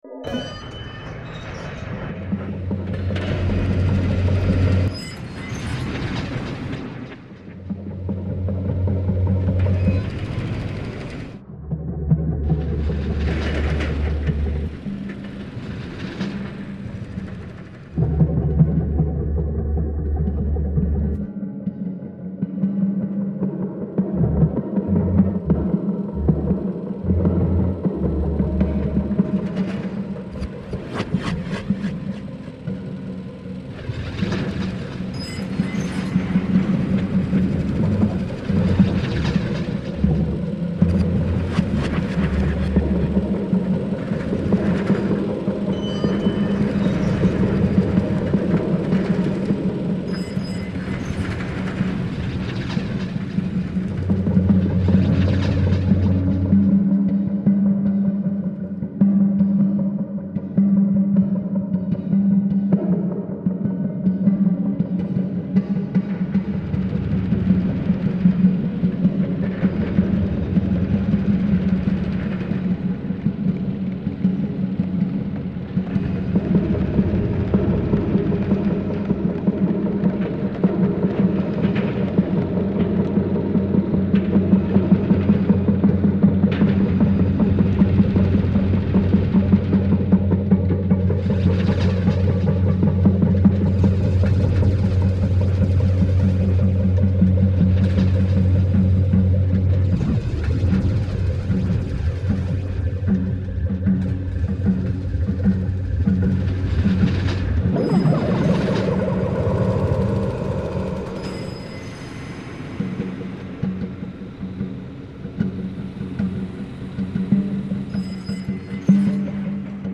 Christchurch demolition reimagined